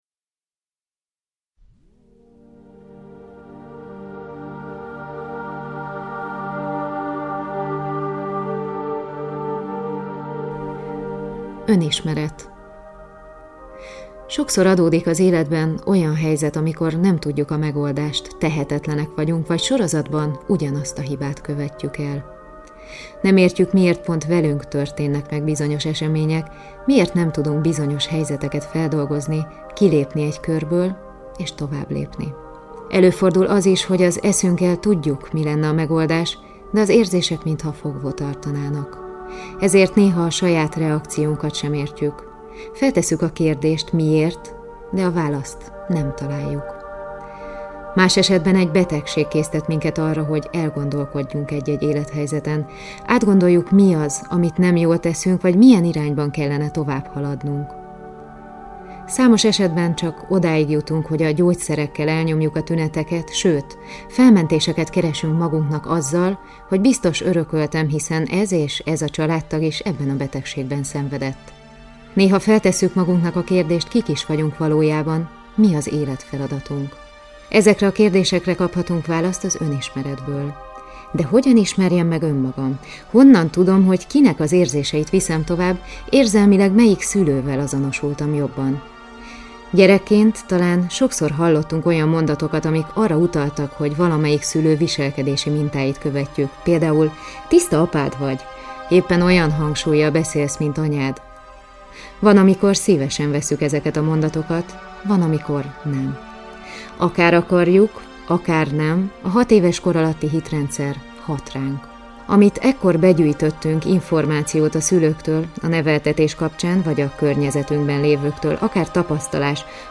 A cd vezetett meditációval segít újra megtapasztalni az Önismerethez elengedhetetlen 6éves kor alatti hitrendszert. Például a létezés, a hatalom, saját identitástudatunk létrejötte.
Mindegyik cd-n Szepes Mária előszava hallható! fenyhaz